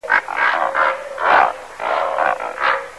Pelikan Różowy - Pelecanus onocrotalus
głosy